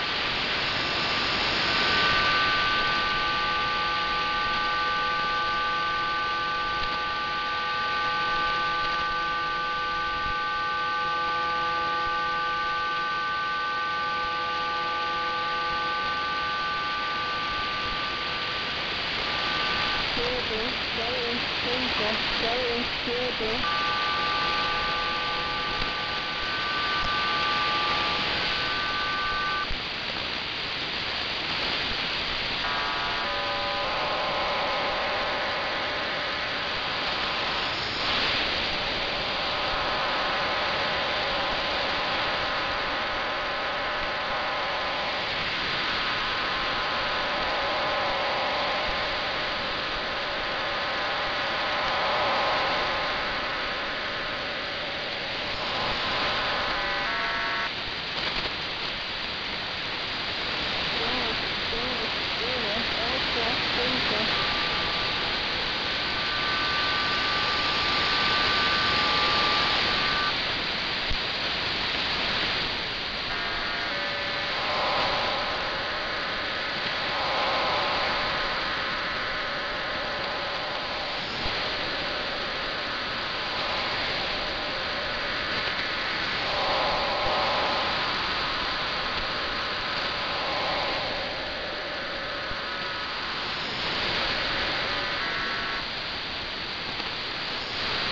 Short recording of station HM01